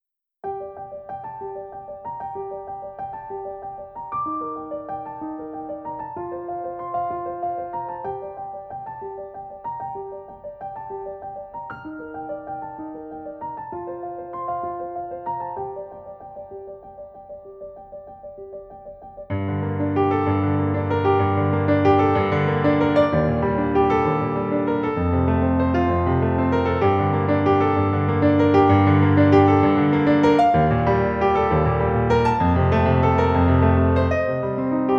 Струнные и рояль
Classical Crossover
Жанр: Классика